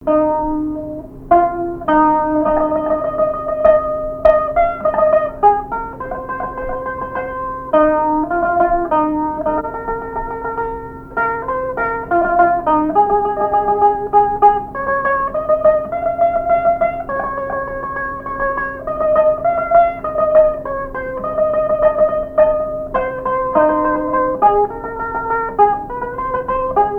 strophique
répertoire au violon et à la mandoline
Pièce musicale inédite